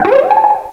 pokeemerald / sound / direct_sound_samples / cries / timburr.aif
timburr.aif